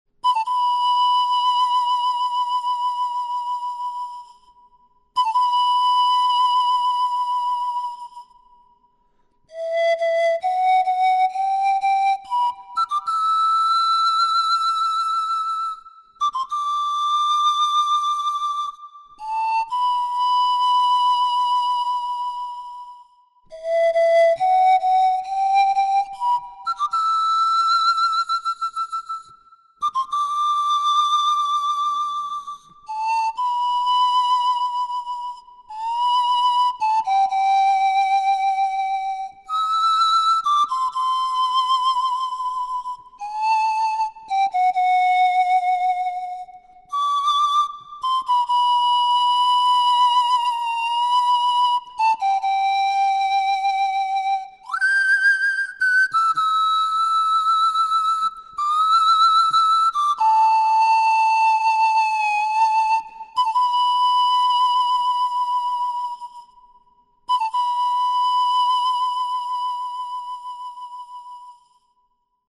Панфлейта UU-22 левосторонняя
Панфлейта UU-22 левосторонняя Тональность: G
Полный альт (соль первой – соль четвертой октавы).
Материал: пластик ABS.